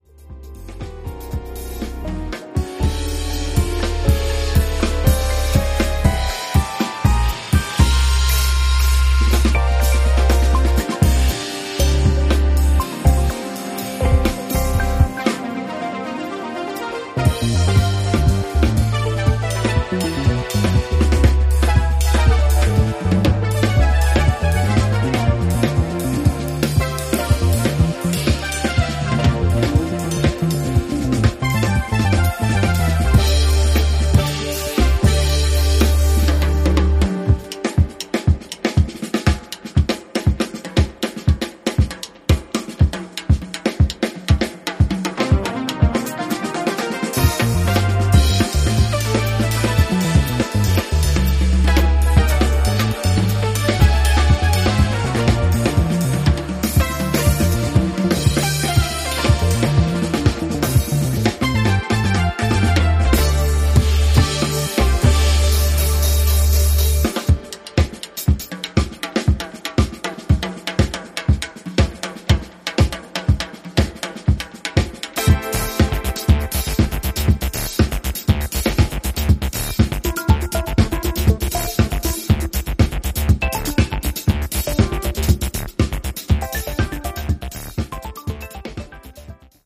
オーセンティックでDJユースなモダン・エレクトリック・フュージョンへと昇華しています。